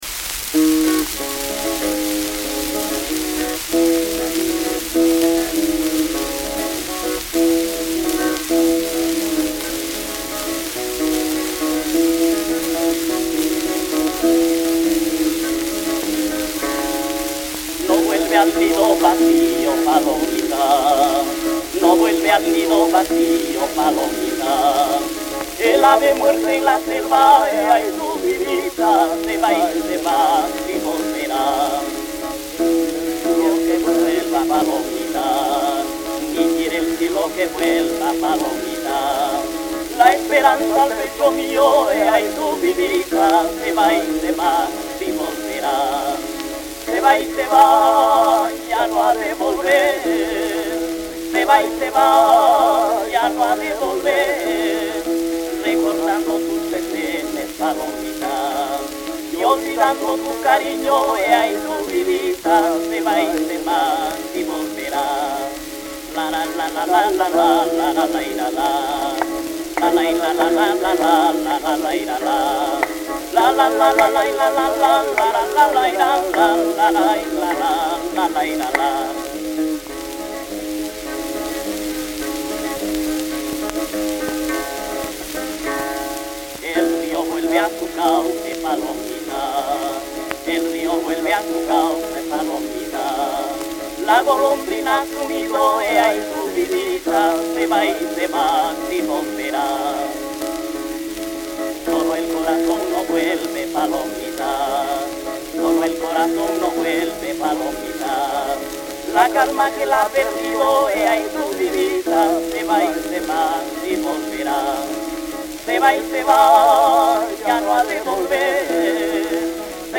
Genre pasacalle